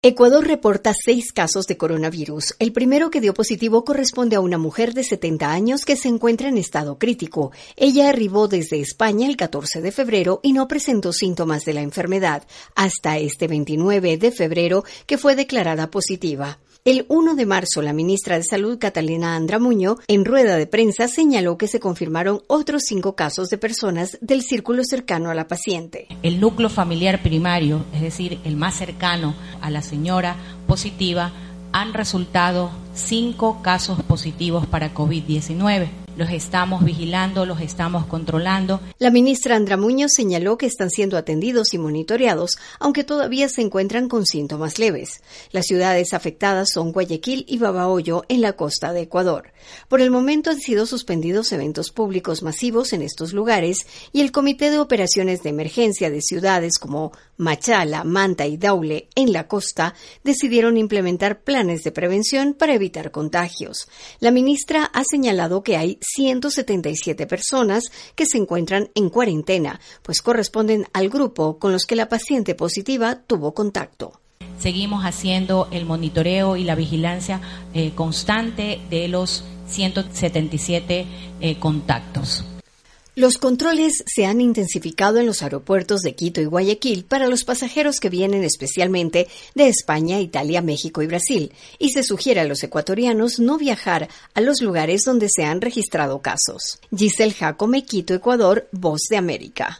VOA: Informe de Ecuador